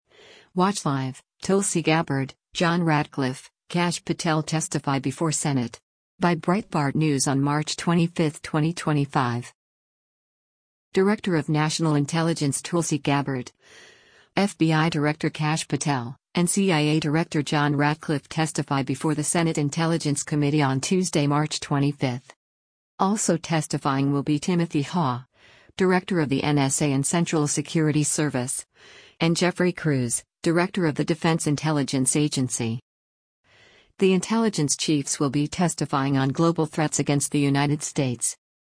Director of National Intelligence Tulsi Gabbard, FBI Director Kash Patel, and CIA Director John Ratcliffe testify before the Senate Intelligence Committee on Tuesday, March 25.